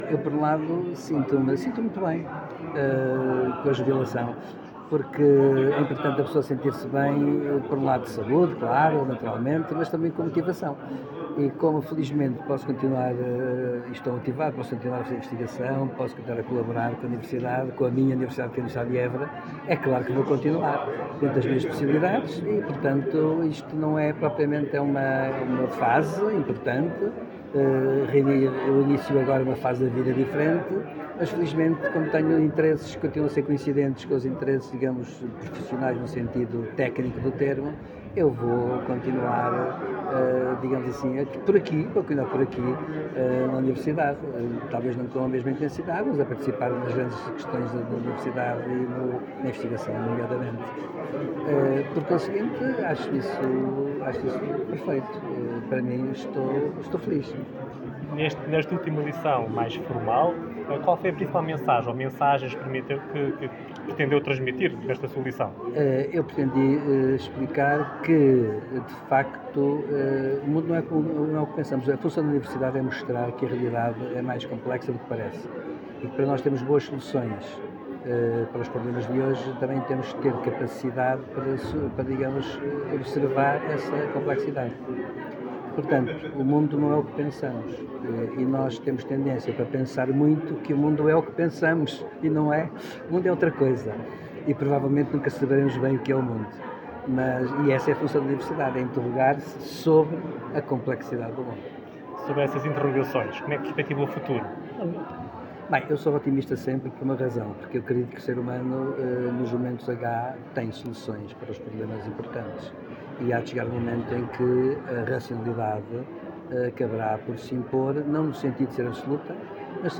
no anfiteatro do Colégio do Espírito Santo